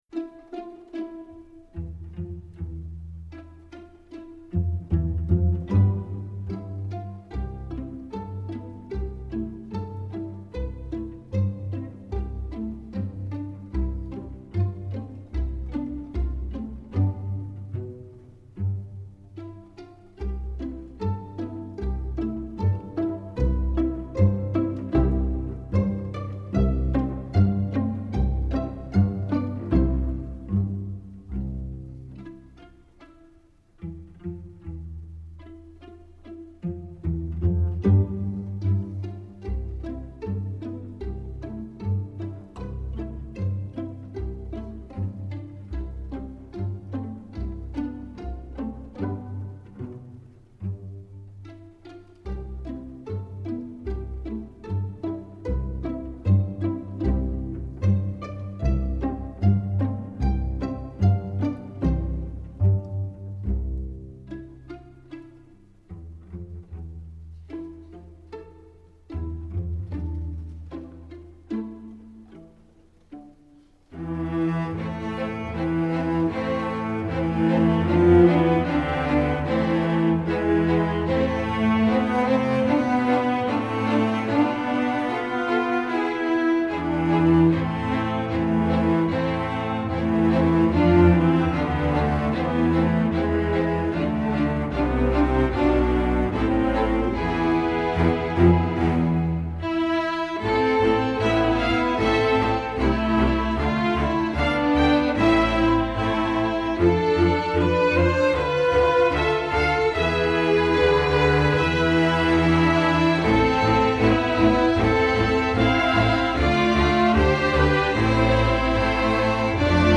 instructional, children